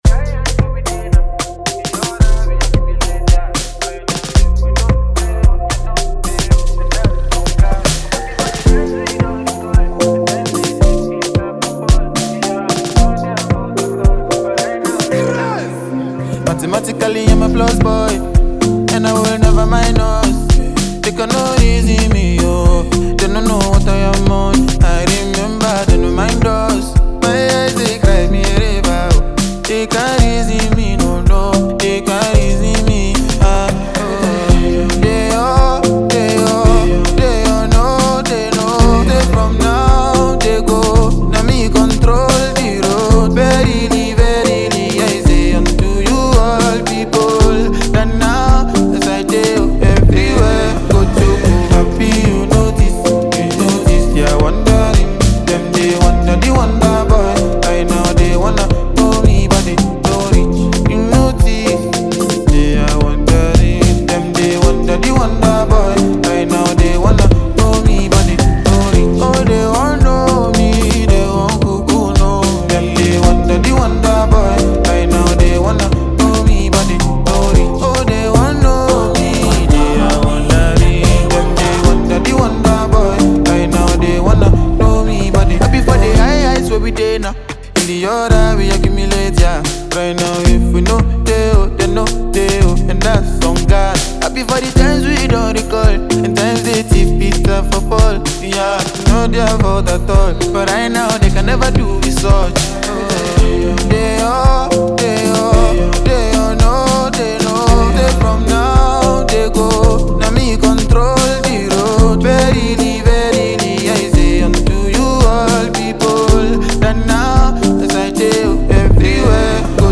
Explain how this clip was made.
Enjoy this dope and clean production.